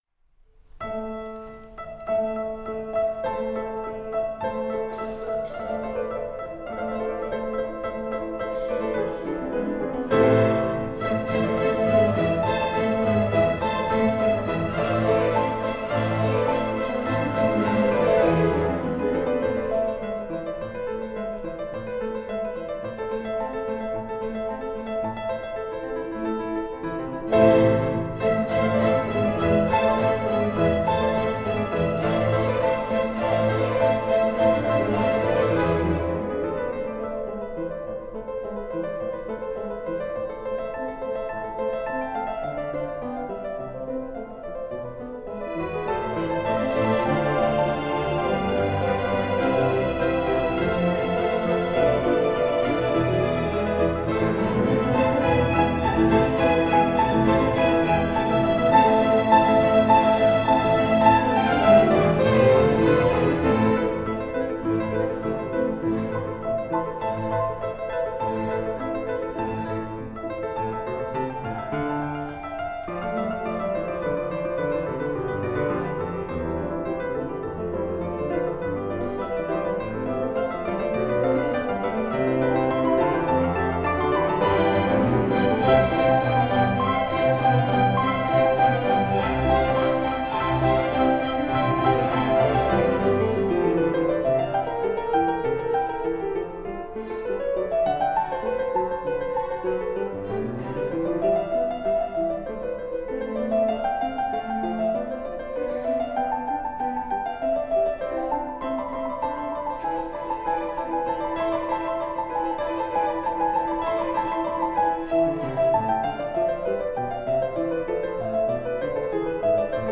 I. Allegro
We performed this on PLU's orchestral series.